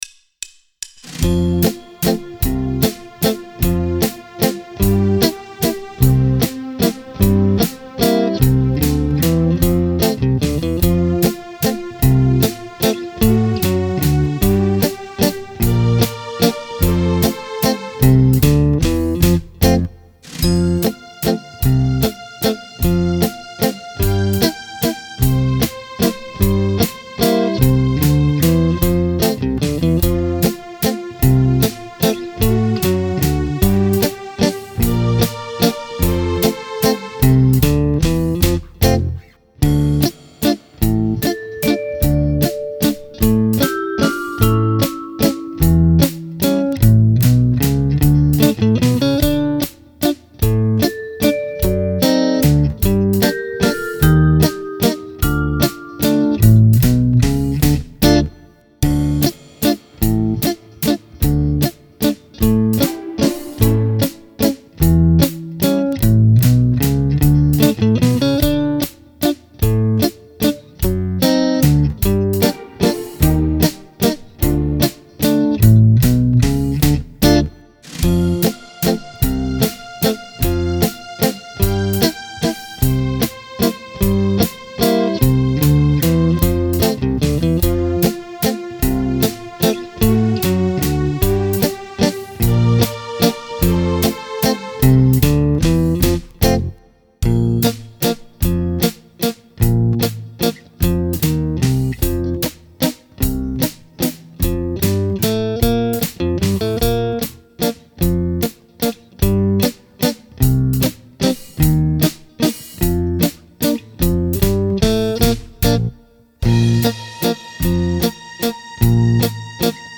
Mazurka